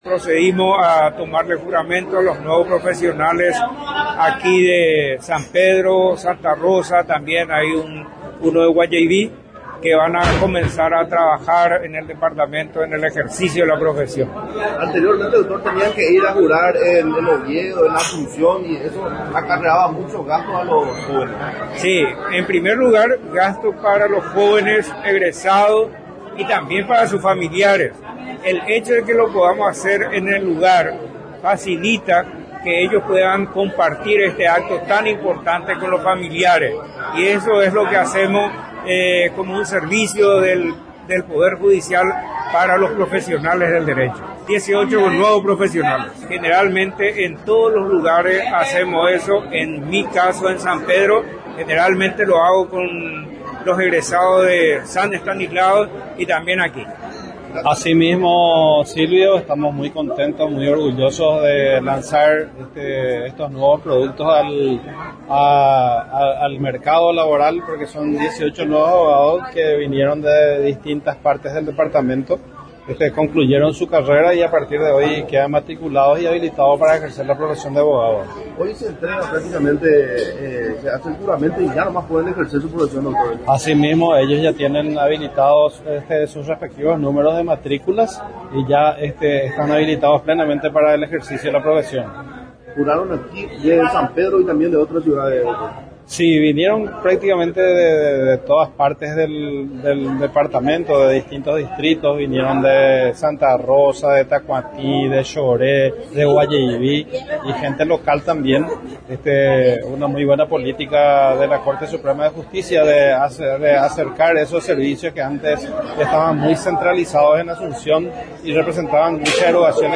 El ministro de la Corte Suprema de Justicia y superintendente de la Circunscripción Judicial de San Pedro, Dr. Manuel De Jesús Ramírez Candia, el pasado viernes encabezó en la capital departamental el acto de juramento de 18 nuevos abogados del segundo departamento.
ACTO-DE-JURAMENTO.mp3